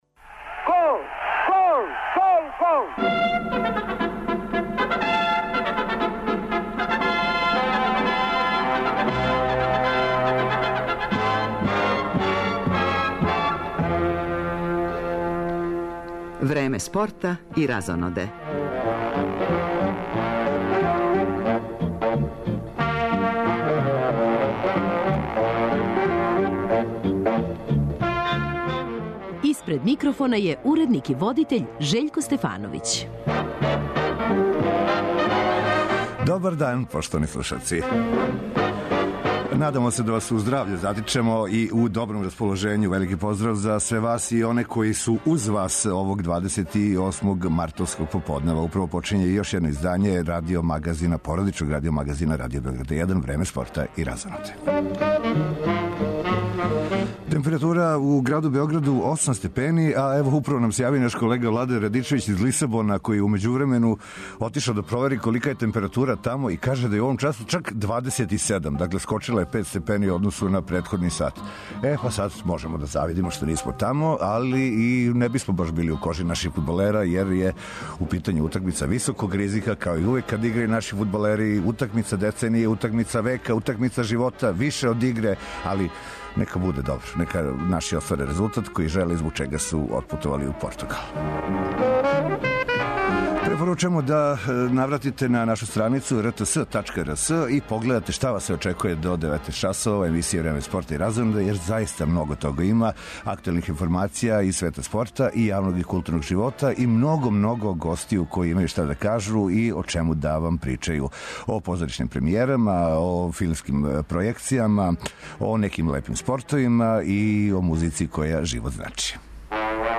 Много гостију биће и ове суботе уживо у студију Радио Београда 1.